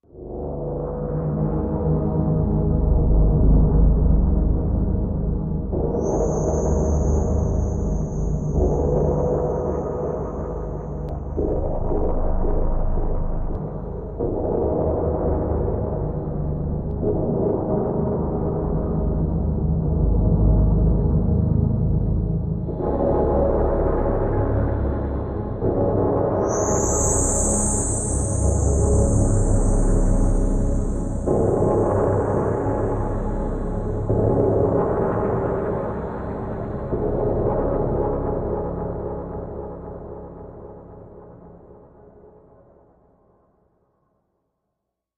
Ambient | Sneak On The Lot
Infinite Air, Machine, Low Ambient, Space, Drone, Moving Air, Atmosphere